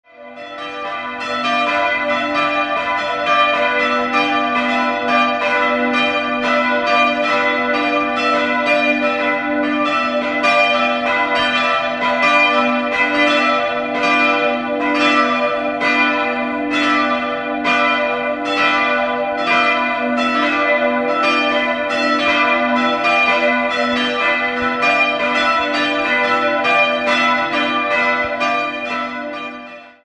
Foto: privat (Kirchengemeinde Gaimersheim) 3-stimmiges TeDeum-Geläute: h'-d''-e'' Die Glocken wiegen 300, 200 und 150 kg und wurden 1959/1962 bei Karl Czudnochowsky in Erding gegossen.